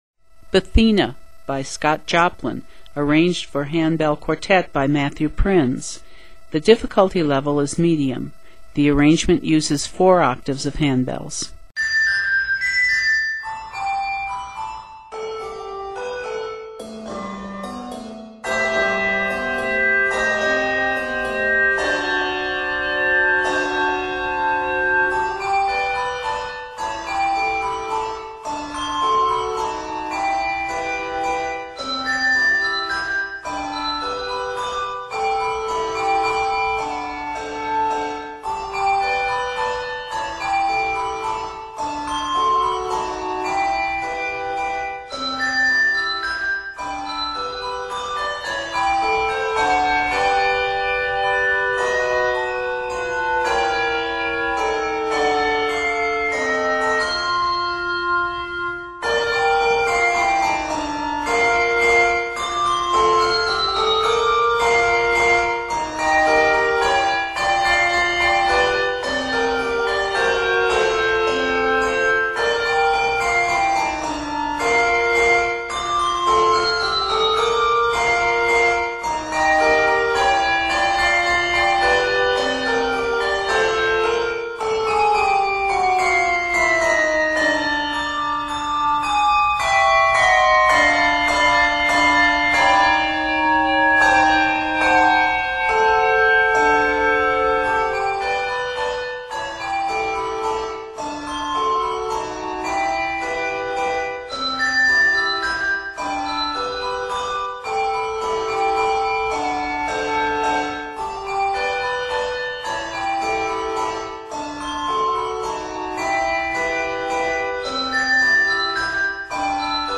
Written in 3/4 time
several key changes
rag time feel
Octaves: 4